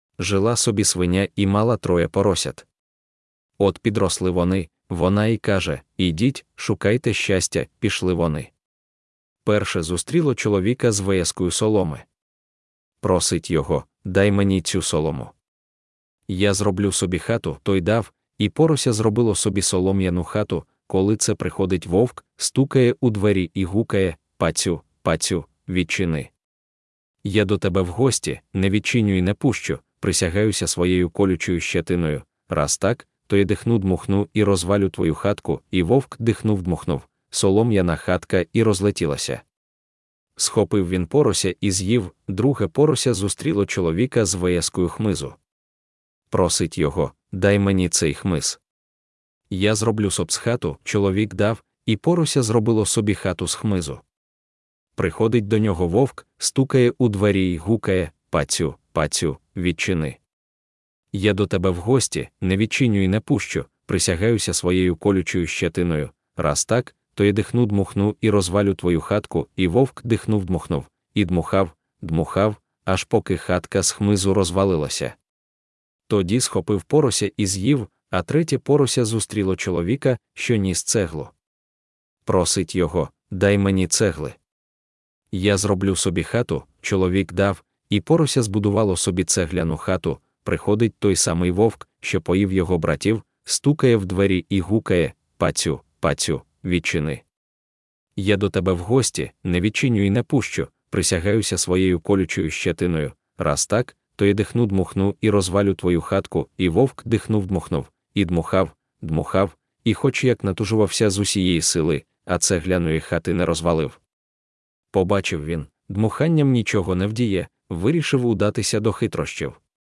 Аудіоказка Троє поросят